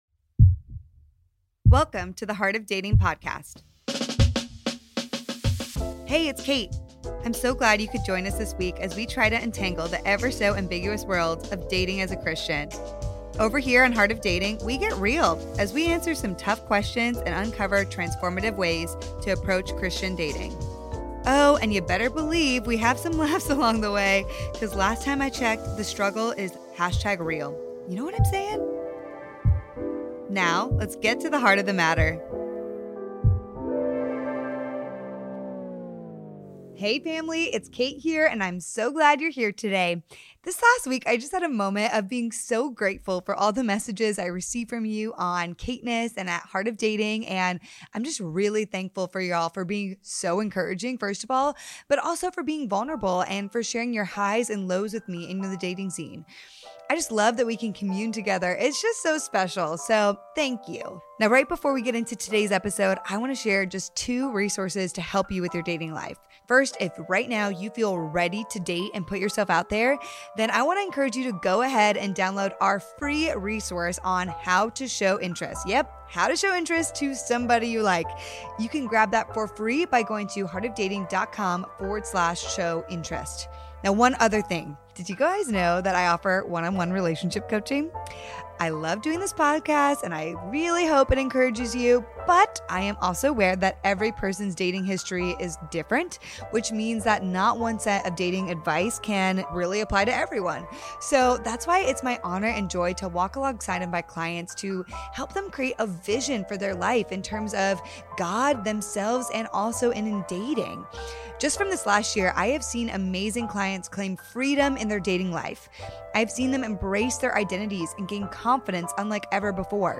It’s an incredibly rich discussion.